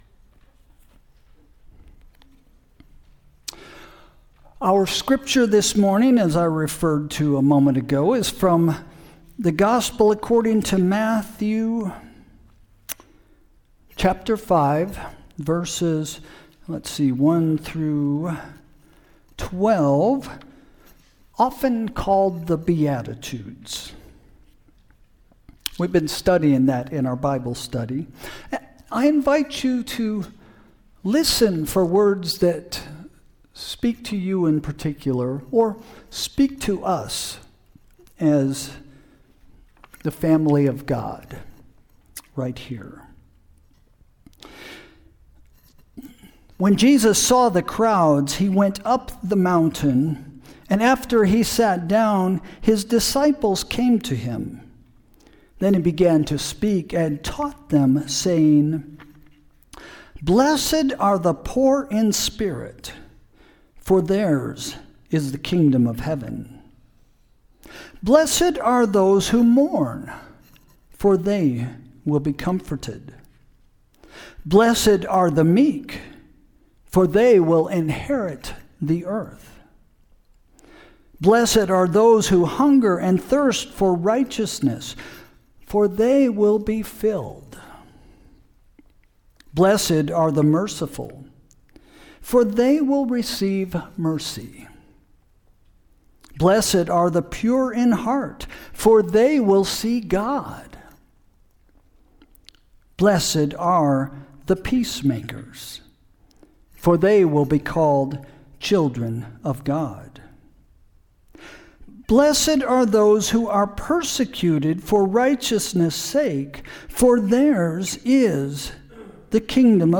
Sermon – March 1, 2026 – “The Good Life”